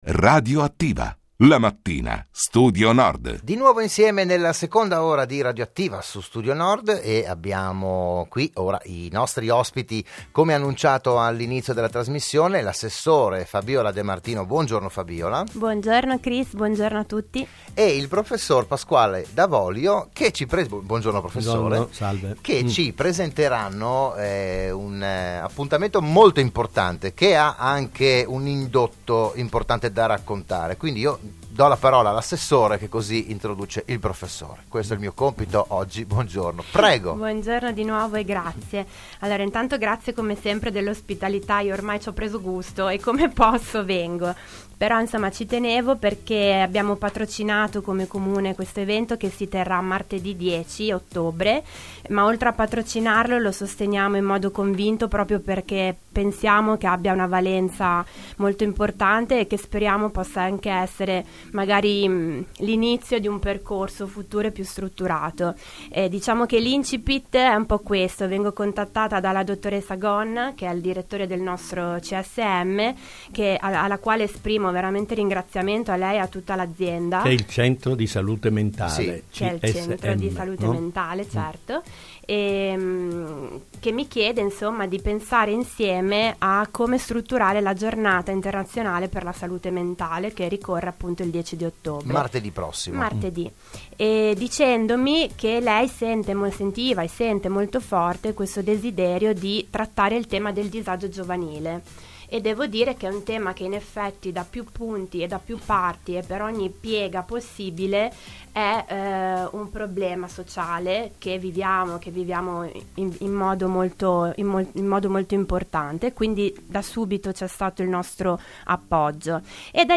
Il PODCAST dell'intervento a Radio Studio Nord